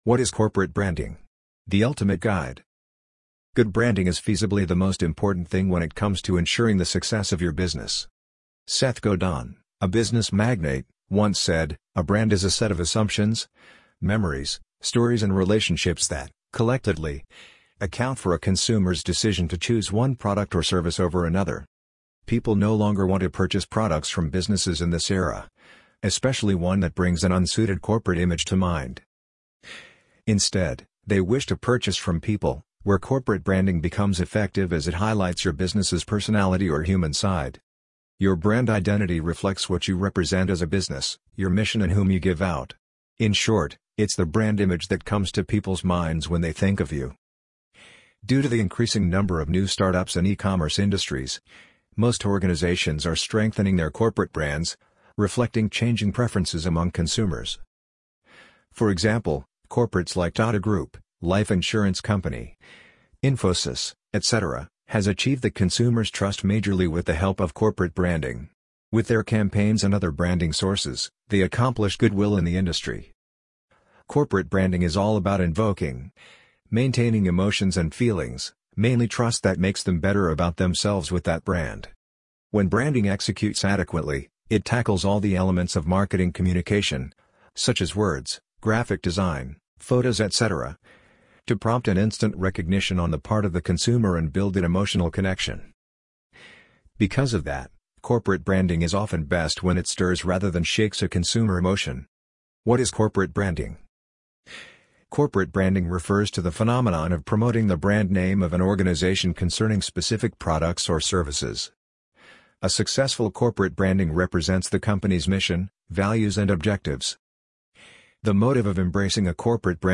amazon_polly_5779.mp3